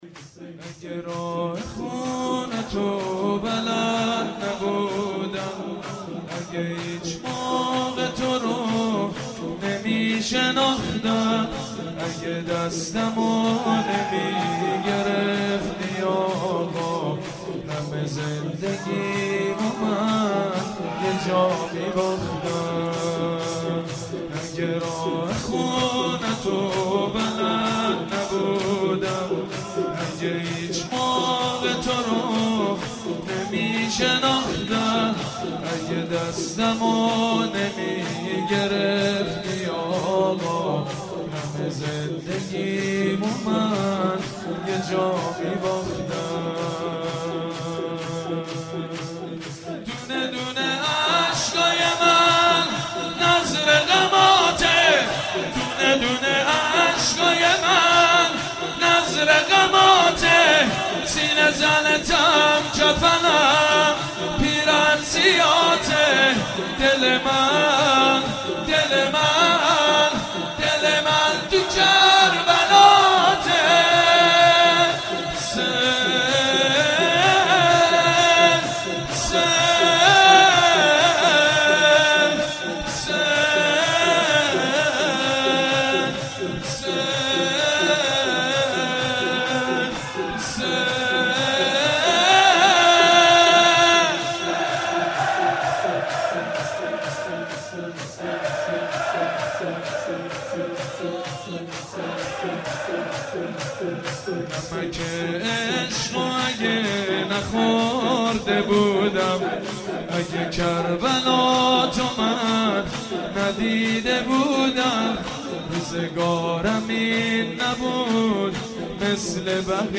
مداحی و نوحه
سینه زنی، شهادت حضرت فاطمه زهرا(س)